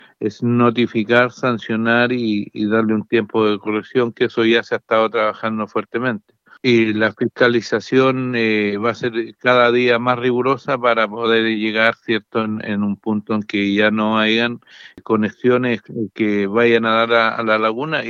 El alcalde de Quillón, Miguel Peña, dijo a La Radio que se ha trabajado durante meses en la solución definitiva del problema que se arrastra hace más de 20 años.